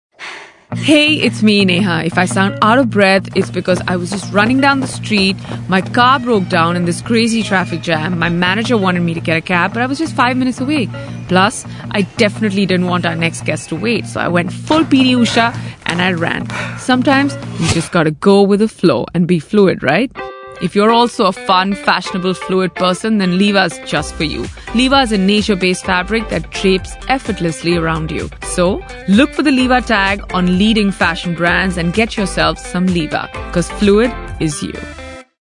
Pre rolls: Voice over by Neha Dhupia
These are 30-second branded audio ads
The aim was to make the audio ad sound like an interaction with the listener rather than an ad that would most likely get ignored.